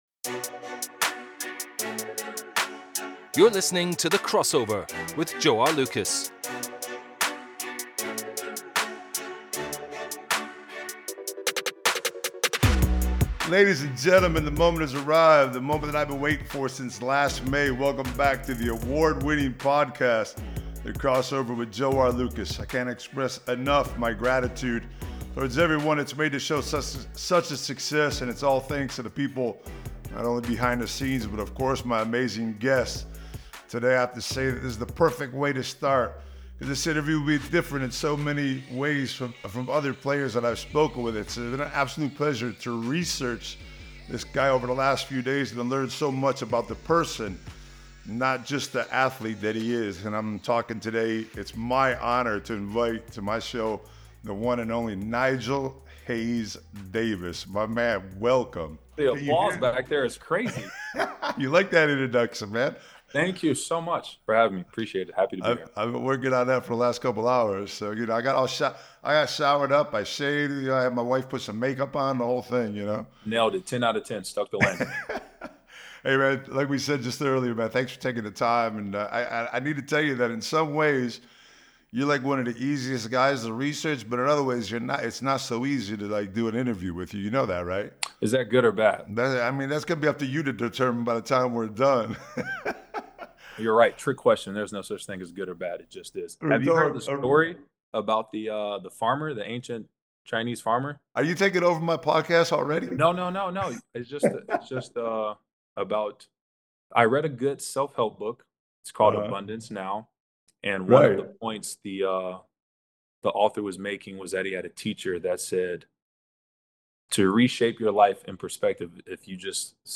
In a wide-ranging interview, Nigel shares with Joe the importance his mother and step-father had in shaping him into the man he is today both on and off the court. They also talk learning languages, Nigel’s ventures into the business world and his basketball experience so far in Europe.